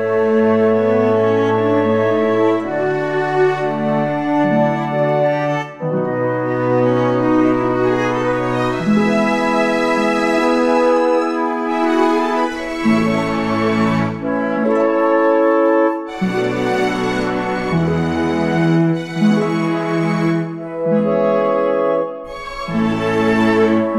no Backing Vocals Soundtracks 2:52 Buy £1.50